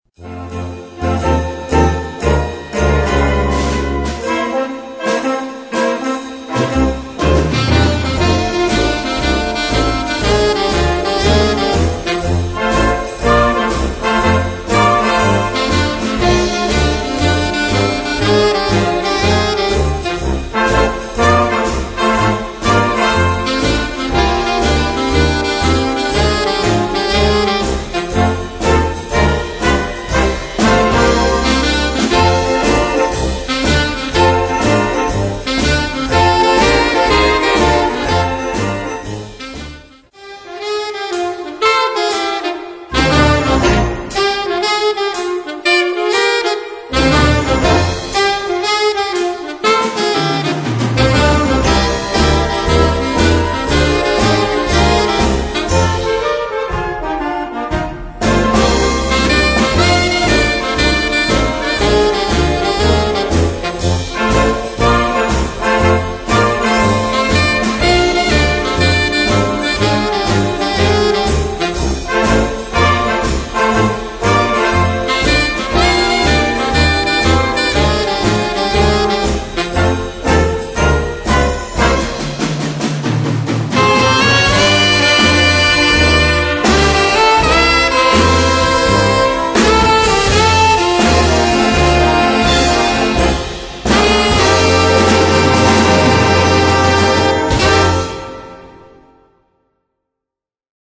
Solo mit Orchester
Noten für flexibles Ensemble, 4-stimmig + Percussion.